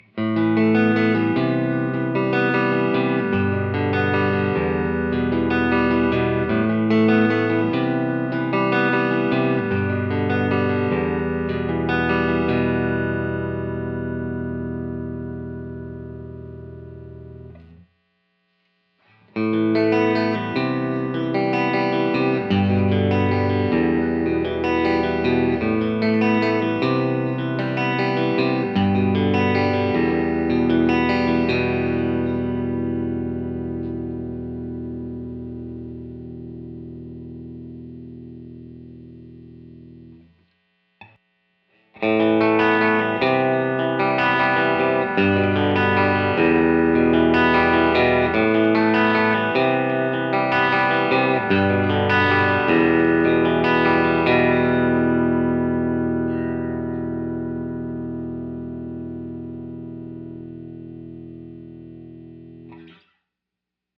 1953 Tele Set Fender Super Reverb / CTS AlNiCo Speakers - Picked Chords
53_Picked_Chords.wav